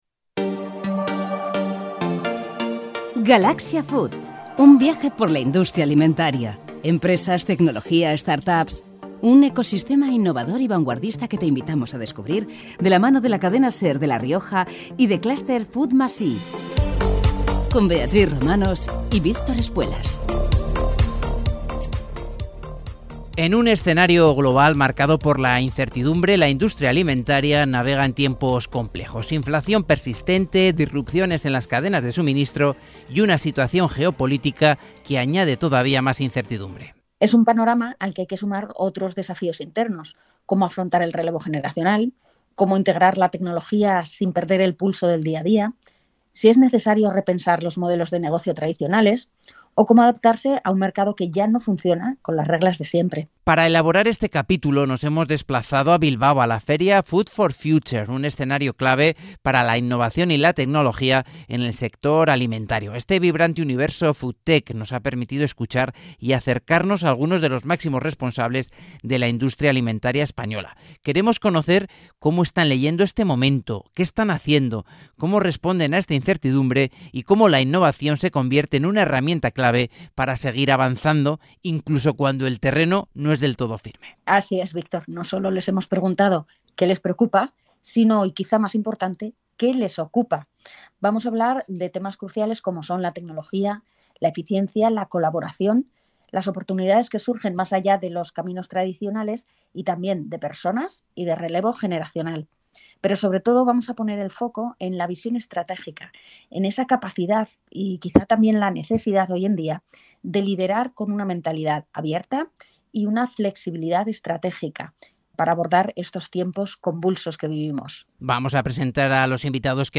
En este capítulo nos acompañan tres directivos de empresas referentes en el sector alimentario del Valle del Ebro.